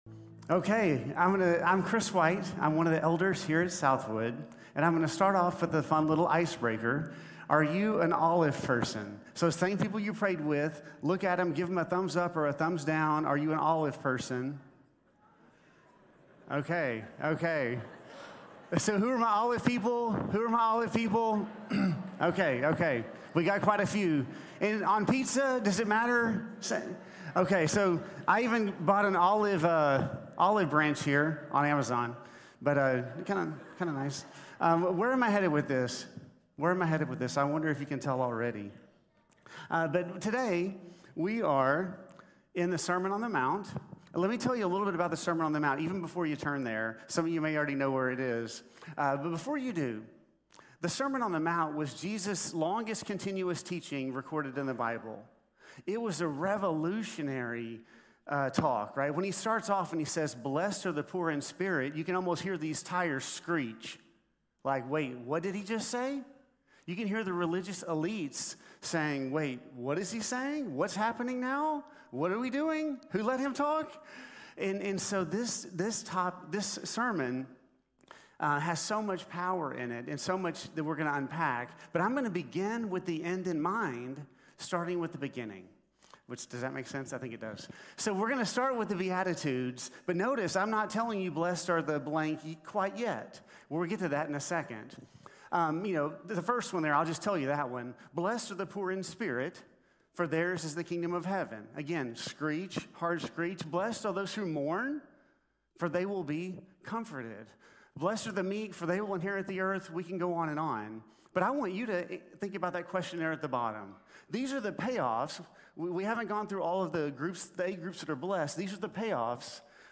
Blessed are the Peacemakers | Sermon | Grace Bible Church